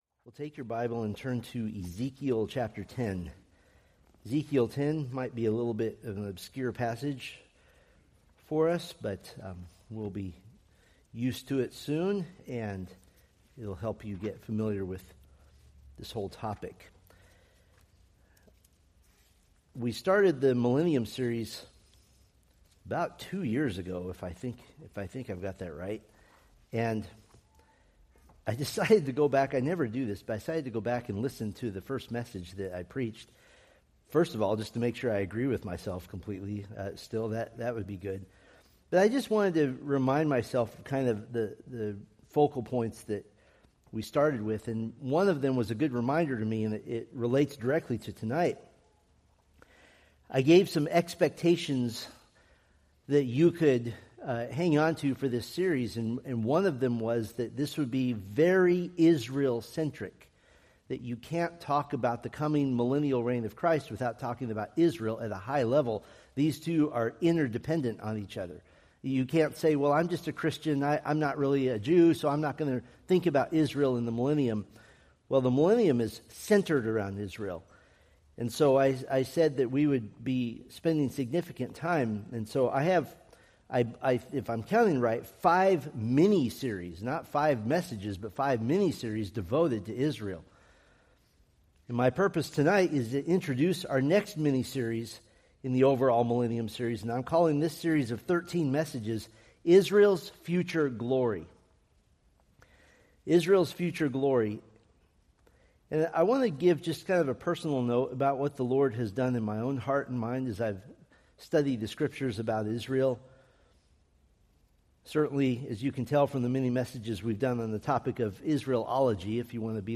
From the Millennium: Israel's Future Glory sermon series.
Sermon Details